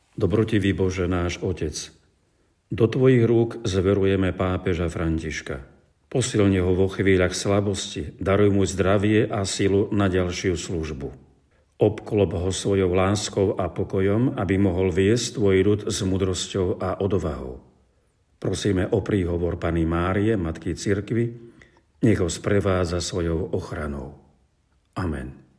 Audionahrávka modlitby (Mons. Bernard Bober)